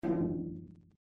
echo.mp3.svn-base